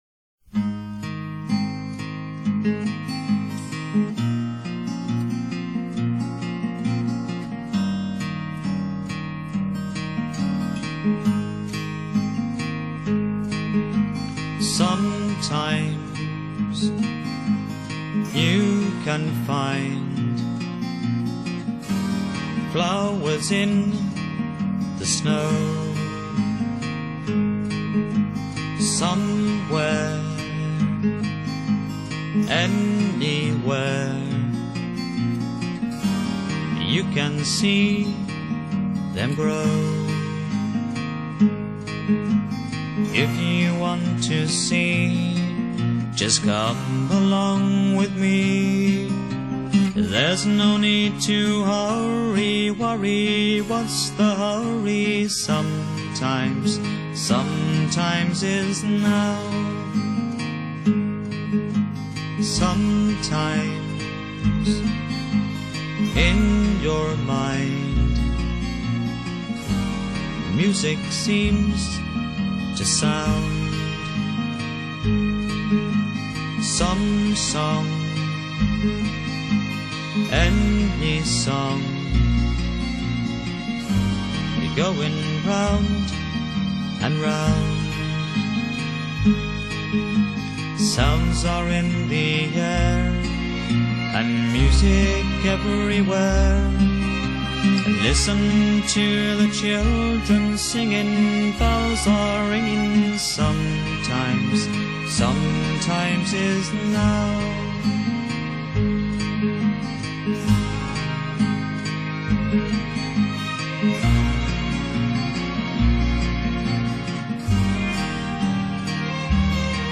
Genre: Folk | Style: Singer/Songwriter
輕柔、穩重、溫暖而抒情
錄音精致、清澈而透明。